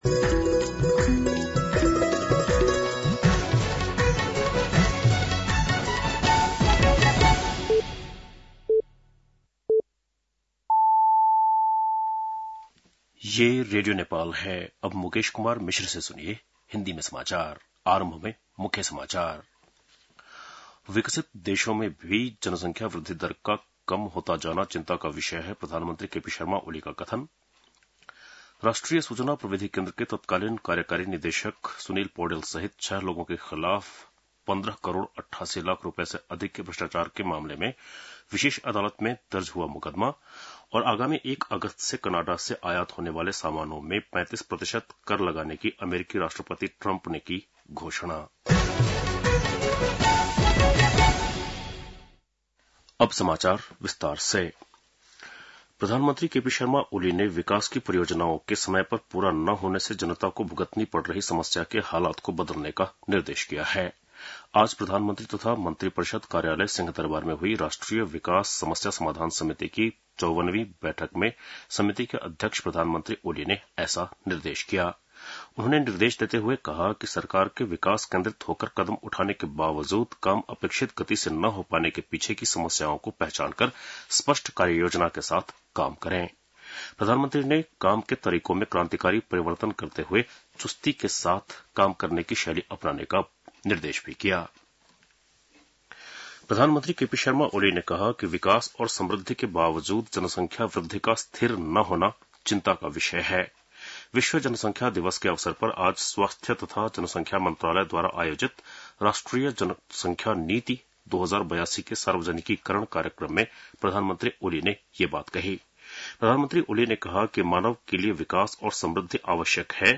बेलुकी १० बजेको हिन्दी समाचार : २७ असार , २०८२